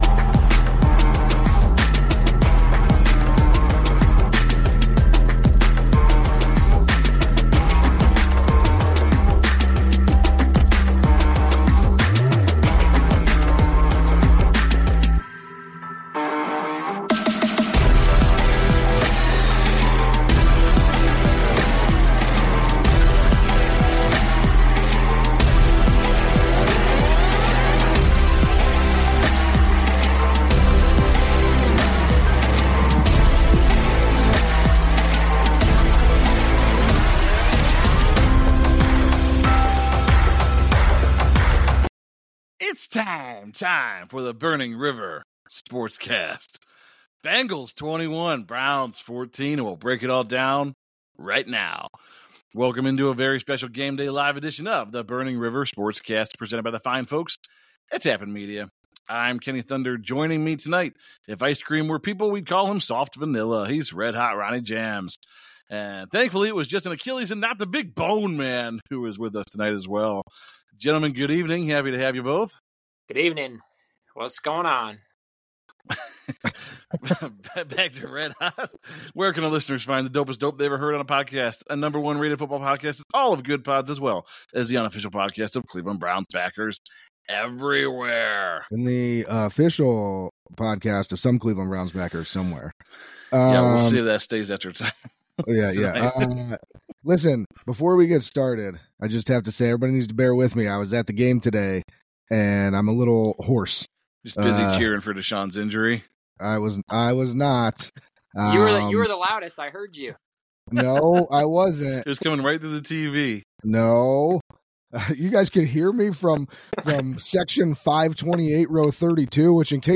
It's time! Time for Burning River Sportscast to go live again! Join as we discuss another disappointing Cleveland Browns loss.